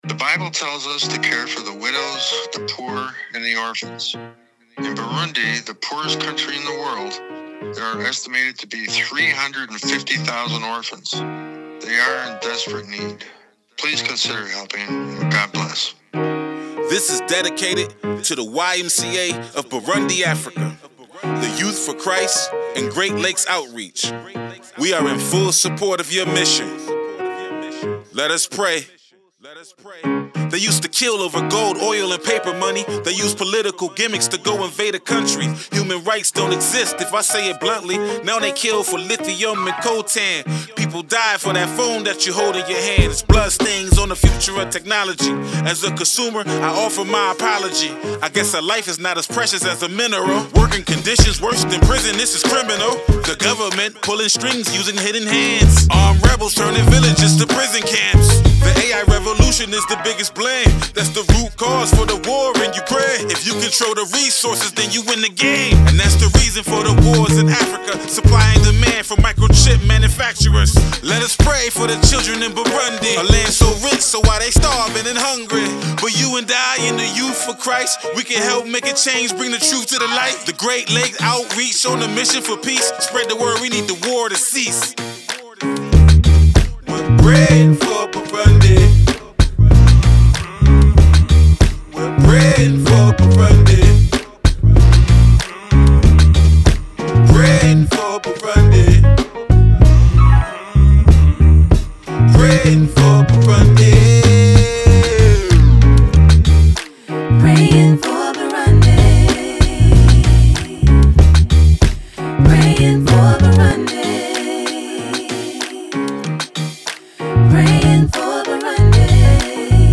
This music video energetically describes the plight of orphans in Burundi and some of the political and social factors associated with their struggle. The rap style hopefully will reach different groups that might not otherwise encounter this message.
"Pray for Burundi" "Pray for Burundi" Rap Song Historically, Rap music has not generally been associated with Christian beliefs and ethics.